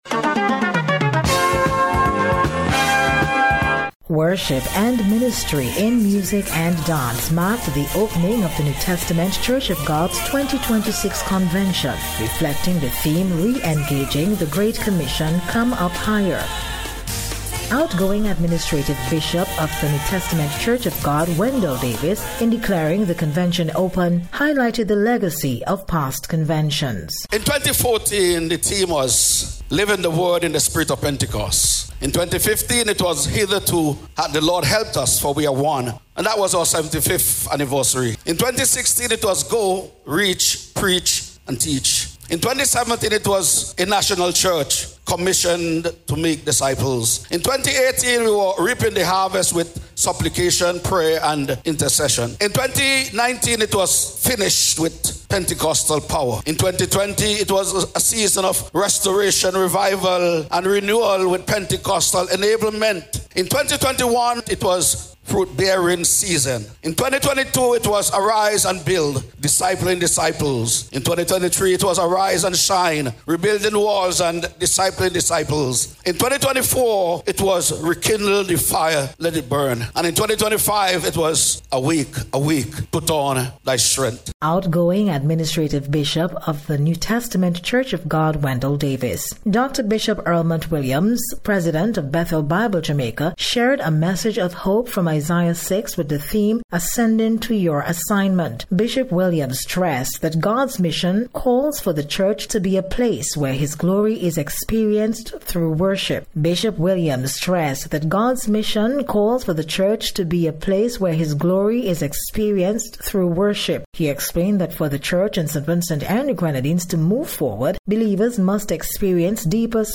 NTCOG-CONVENTION-SPECIAL-REPORT.mp3